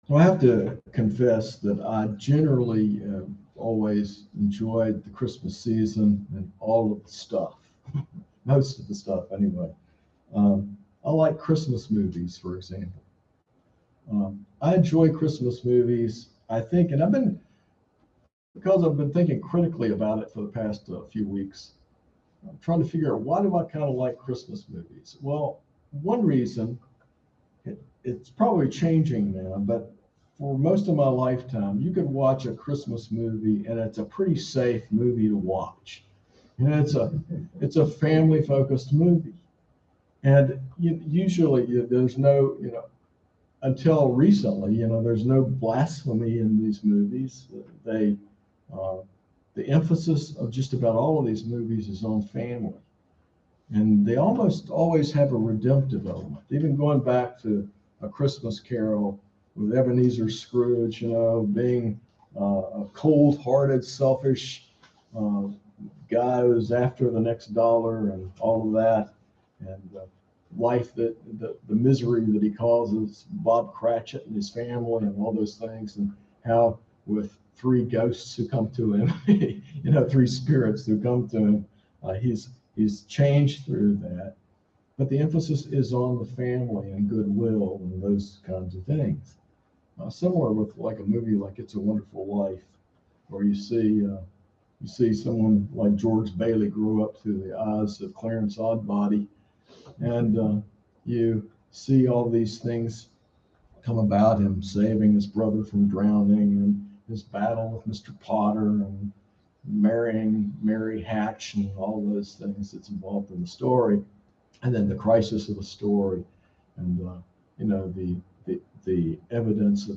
This sermon emphasizes the importance of Christ as the true focus of Christmas, highlighting how His significance as Creator, King of Kings, and Savior should never be forgotten or taken for granted, especially in a world that often overlooks Him.